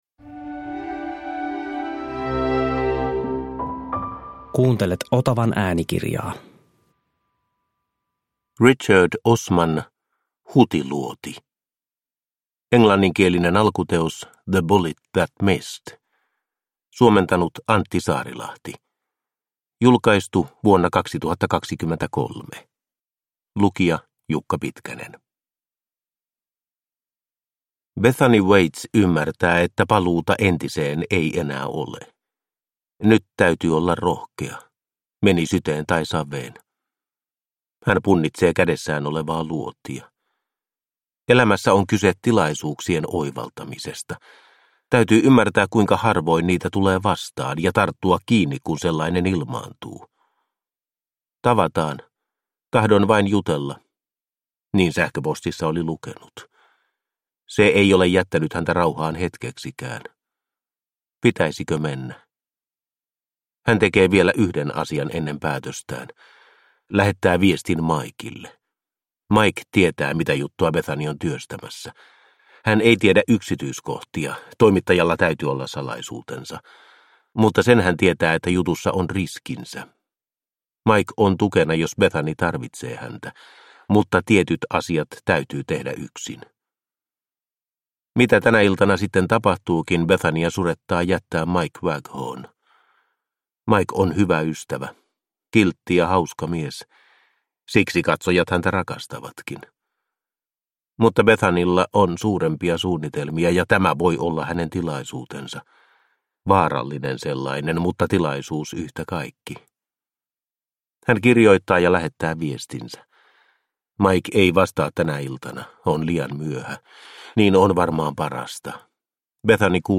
Hutiluoti – Ljudbok – Laddas ner